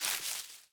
Minecraft Version Minecraft Version 1.21.5 Latest Release | Latest Snapshot 1.21.5 / assets / minecraft / sounds / block / leaf_litter / step5.ogg Compare With Compare With Latest Release | Latest Snapshot